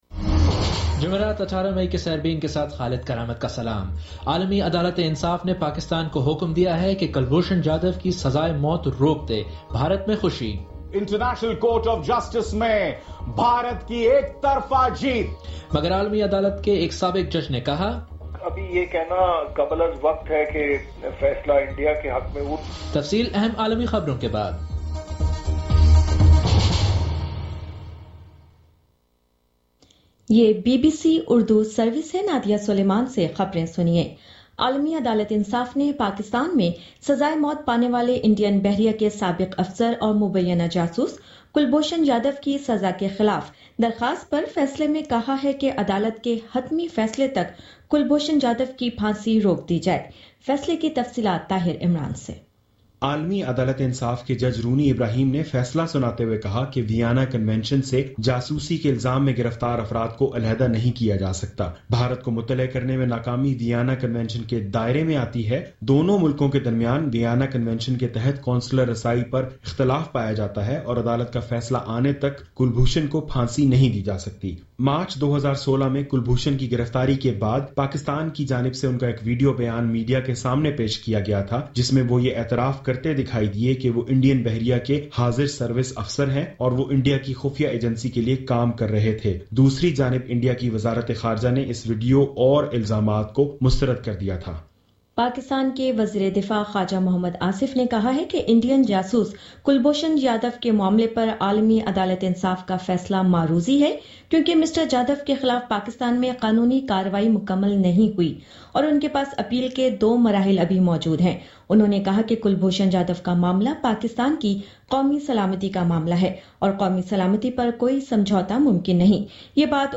جمعرات 18 مئی کا سیربین ریڈیو پروگرام